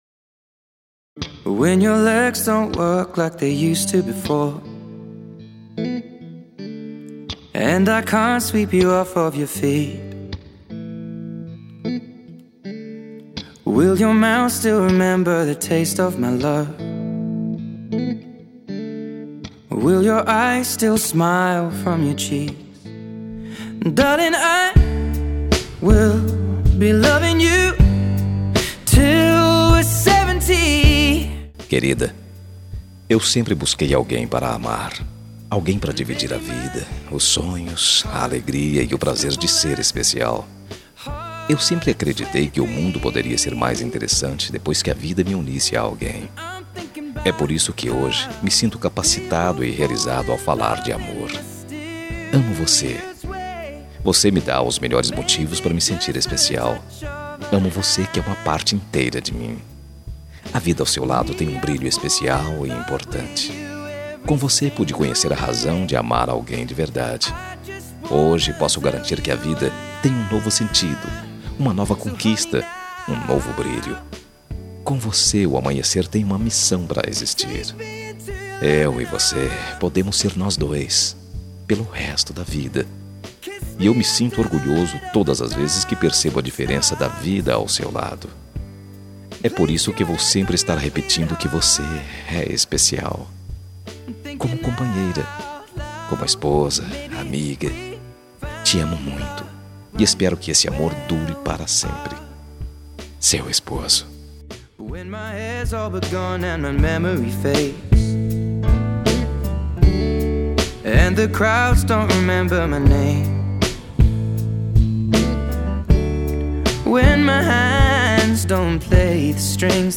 Telemensagem Romântica para Esposa – Voz Masculina – Cód: 9070 – Linda